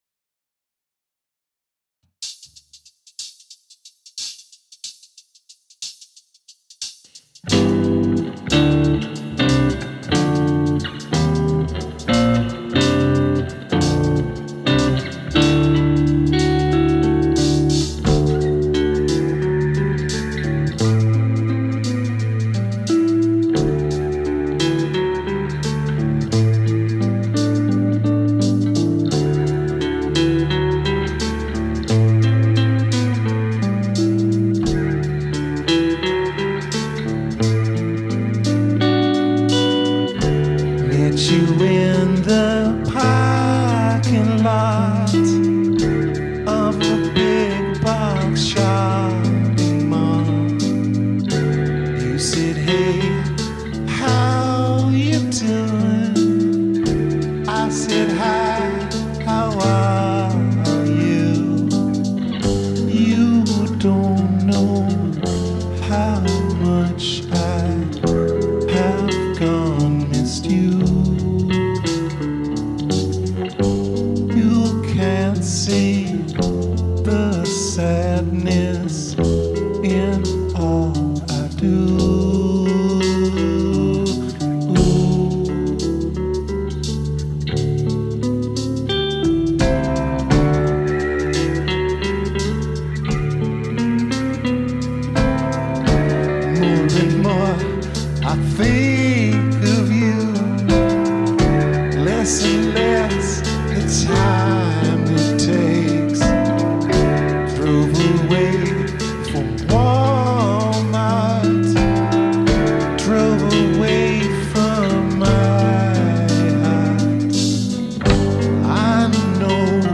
piano
as usual these were rehearsal takes with lots of problems but moving on 🙂
The Available
Rehearsal recordings